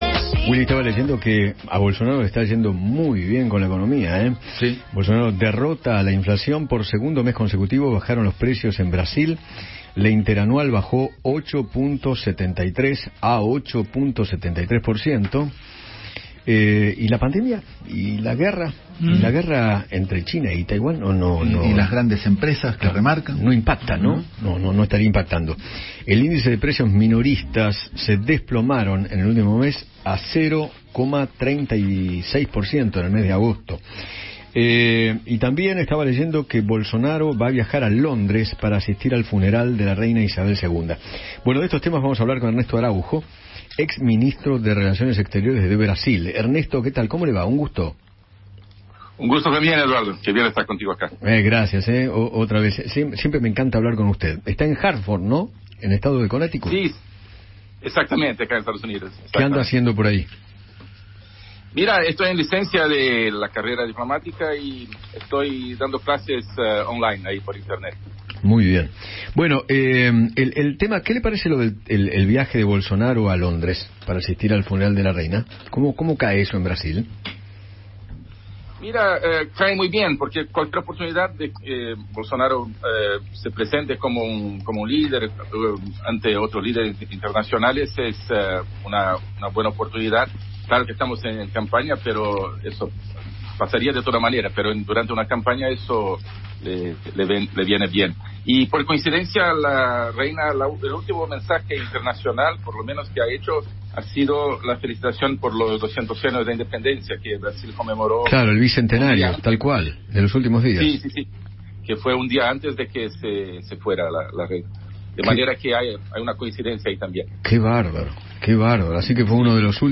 Ernesto Henrique Fraga Araújo, ex ministro de Relaciones Exteriores de Brasil, habló con Eduardo Feinmann sobre la situación política de su país y se refirió a las próximas elecciones presidenciales.